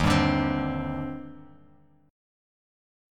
D#M13 Chord
Listen to D#M13 strummed